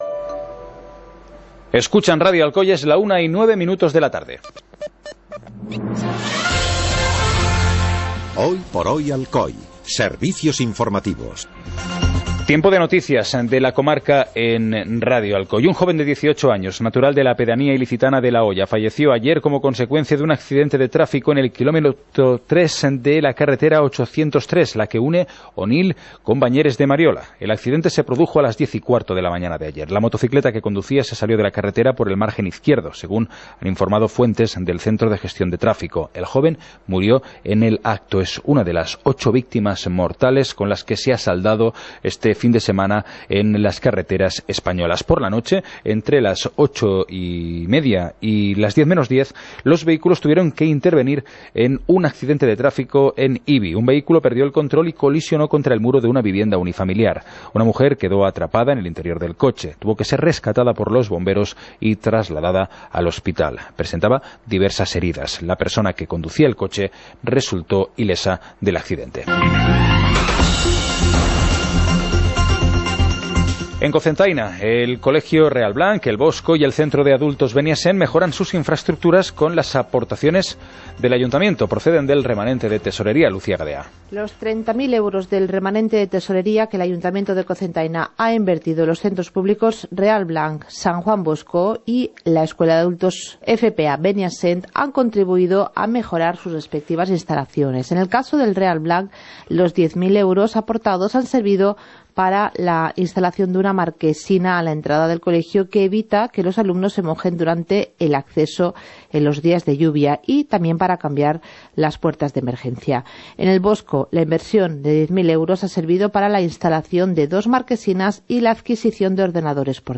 Informativo comarcal - lunes, 26 de febrero de 2018